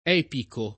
DOP: Dizionario di Ortografia e Pronunzia della lingua italiana
epico [ $ piko ]